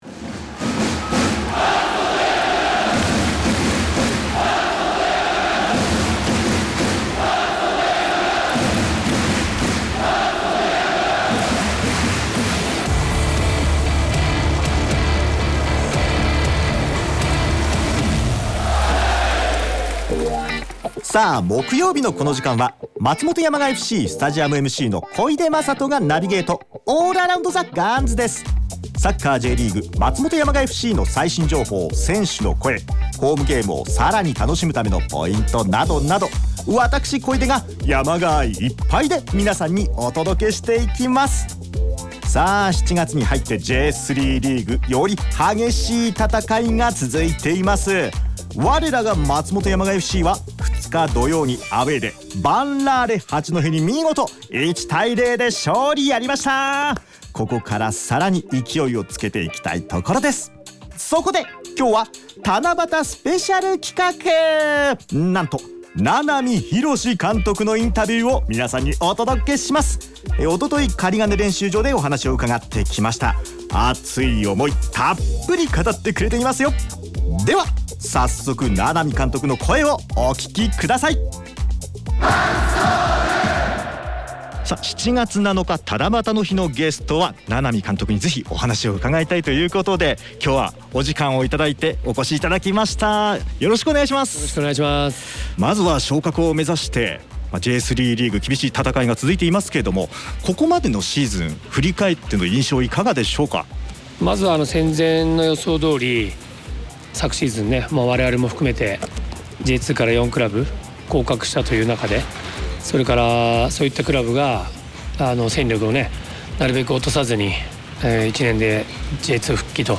7月7日放送分：名波浩監督インタビュー！